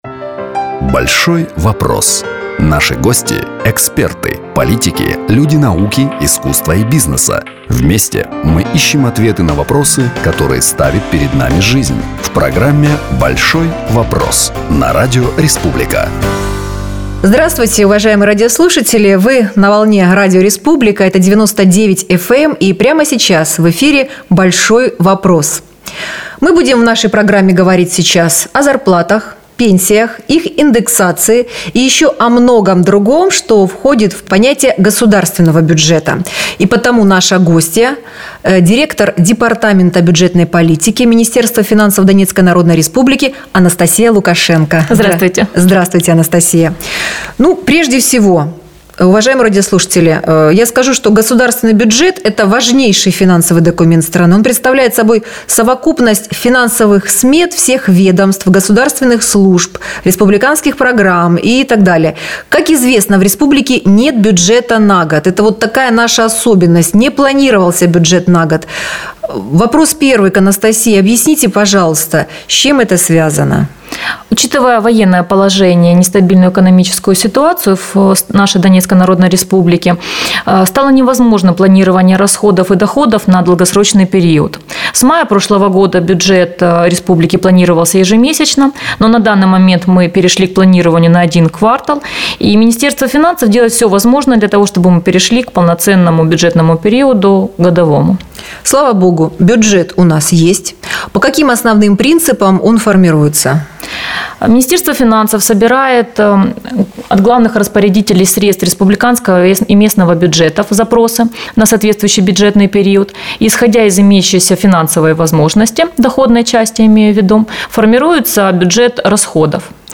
на волнах радио «Республика» (99.0 FM) в программе “Большой вопрос”